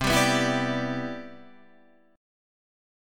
C Major 9th